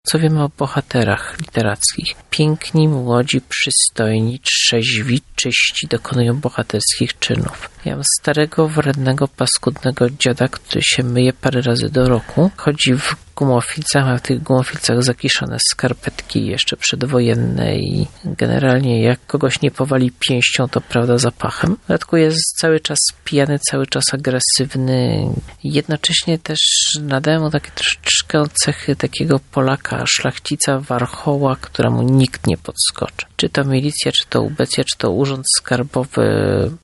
Dlaczego takim fenomenem stał się Jakub Wędrowycz, tłumaczy jego twórca – pisarz Andrzej Pilipiuk